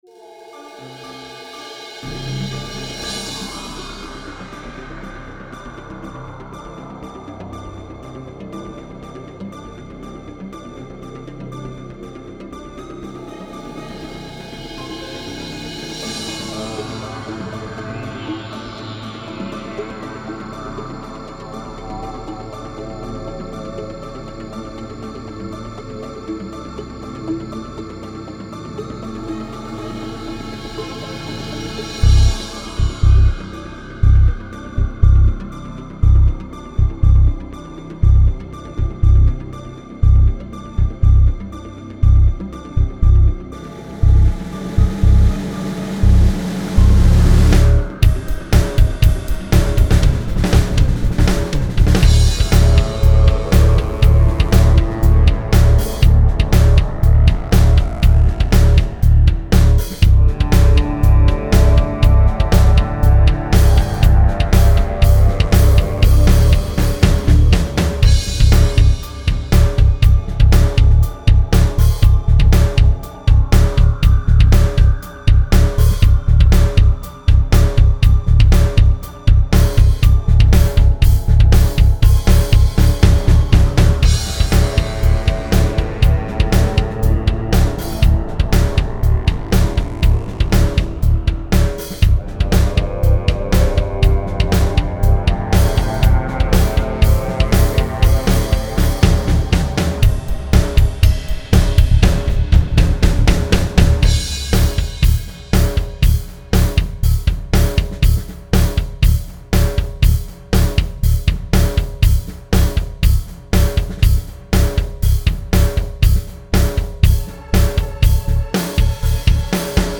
New Psychedelic Rock